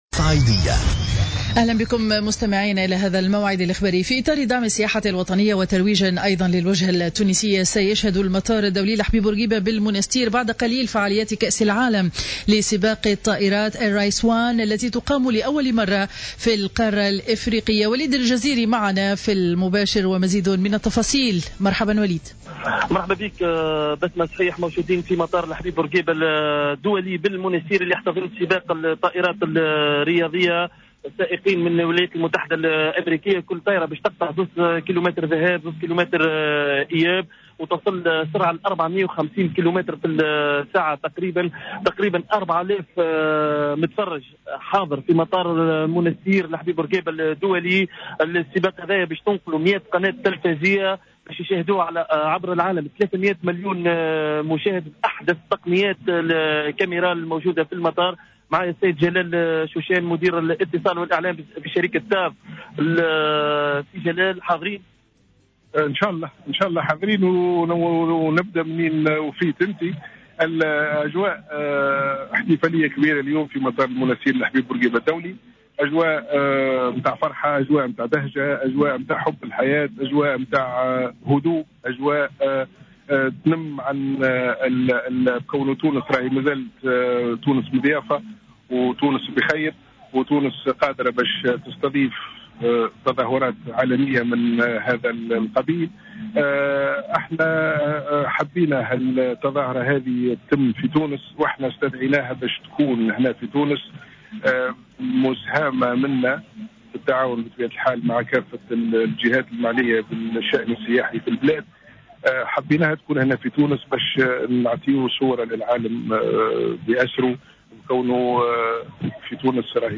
نشرة أخبار منتصف النهار ليوم الأحد 07 جوان 2015